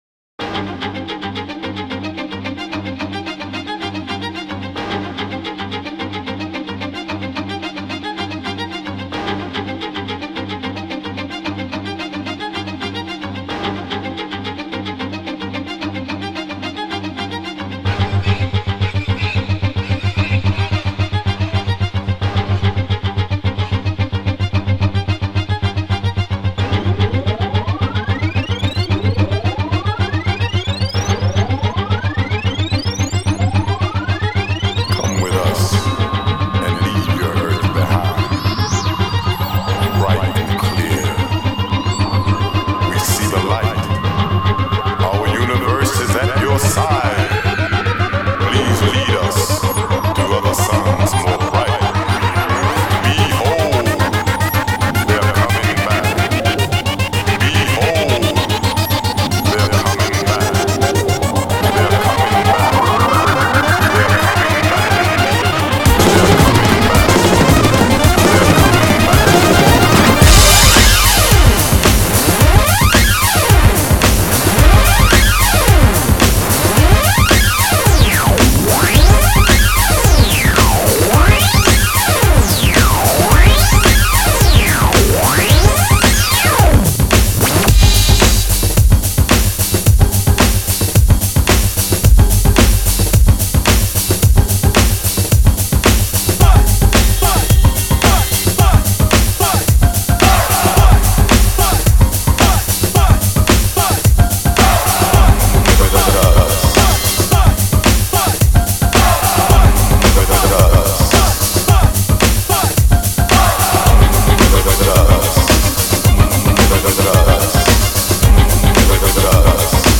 mind-altering dance music even your mom can get behind.